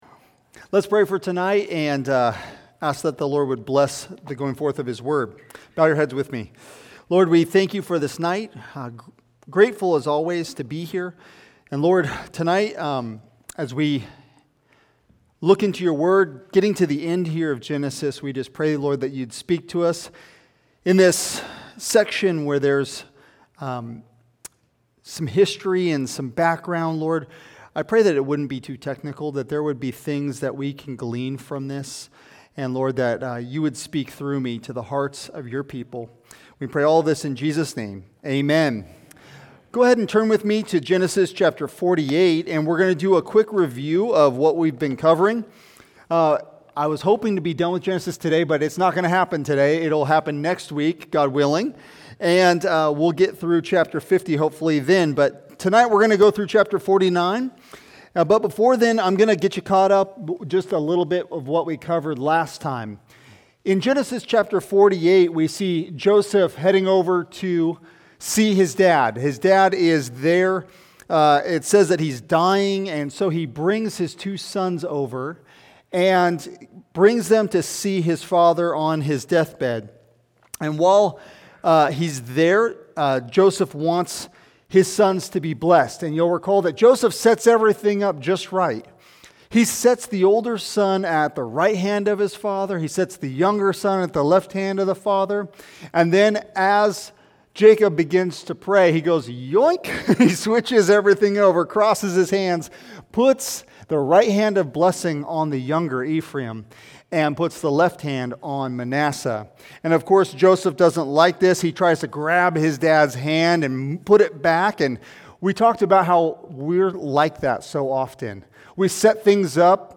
Sermons | Heritage Bible Church of Tri Cities